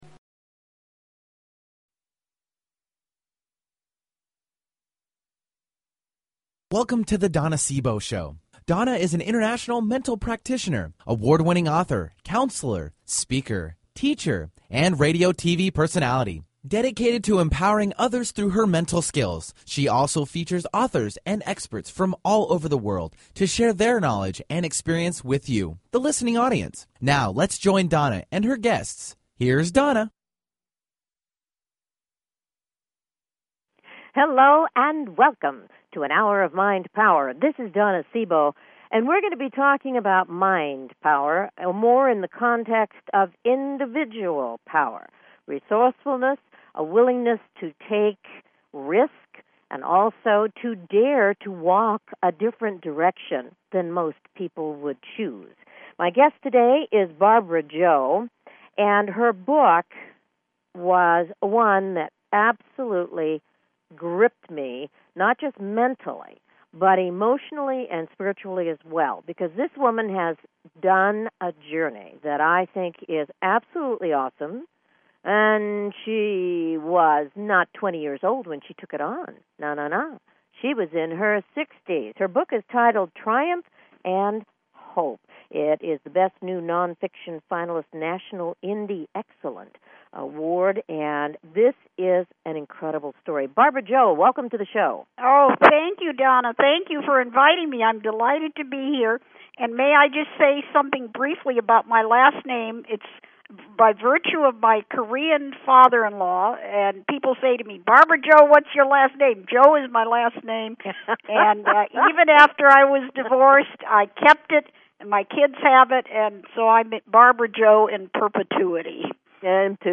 Talk Show Episode
Guests on her programs include CEO's of Fortune 500 companies to working mothers. Her interviews embody a golden voice that shines with passion, purpose, sincerity and humor.